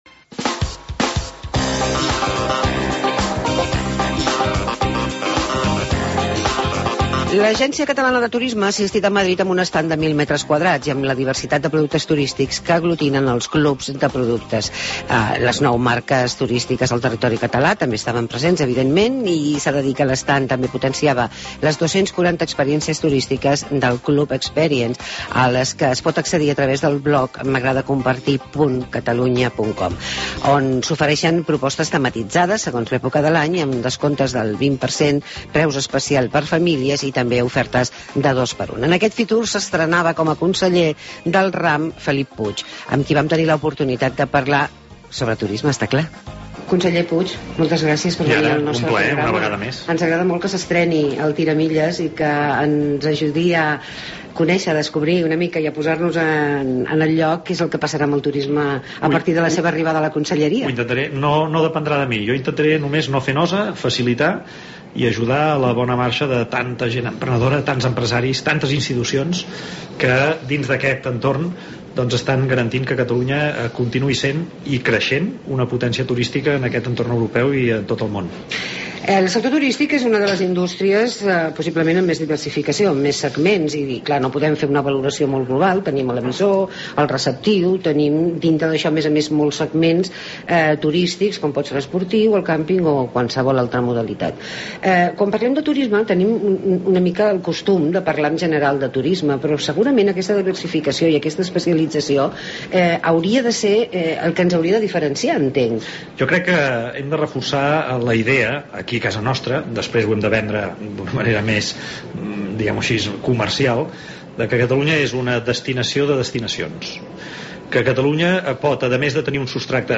Entrevista al conseller de turisme Felip Puig